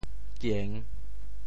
獧 部首拼音 部首 犭 总笔划 16 部外笔划 13 普通话 juàn 潮州发音 潮州 giêng3 文 中文解释 狷 <形> 拘谨无为。